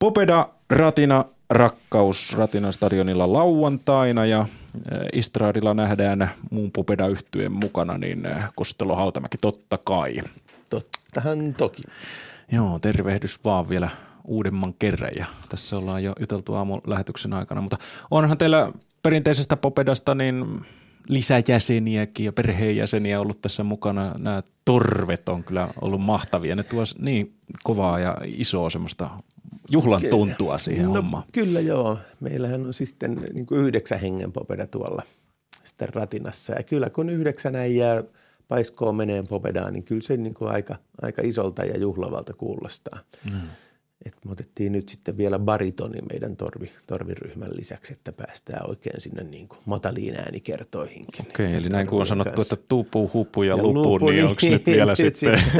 Päivän vieras